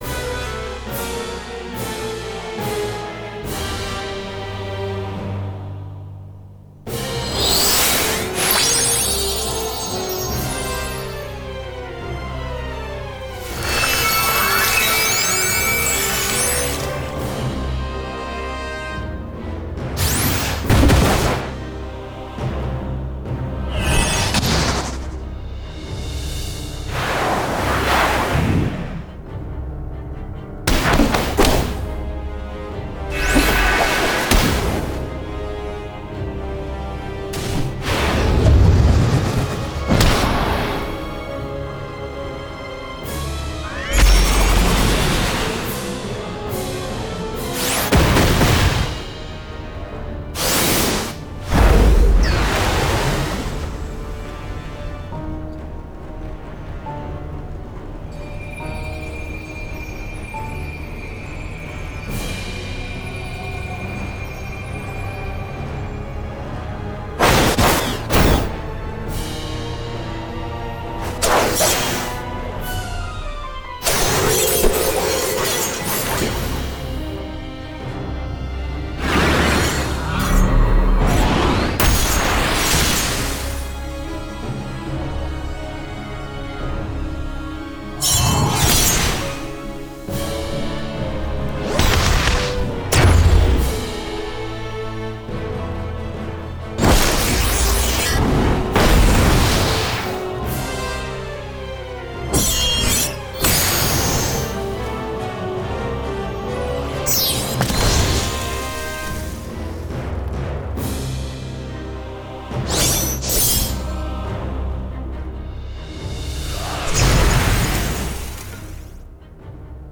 446魔法音效
与我们的RPG Magic音效包类似，我们的音效来自不同的层次，例如“投射”，“冲击”，“射击”，甚至还有更多JRPG设计的咒语层，可为您的游戏创建最合适的魔法声音。
• 魔法攻击与冲击（包括基本的飞快移动，复古的JRPG风格和现代的RPG风格法术）
• 元素法术（火，风，水，植物，电和冰）
• 武器和设计的法术（魔术拳，斜杠，组合法术，VFX启发法术）
• 拳，剑斜线等等！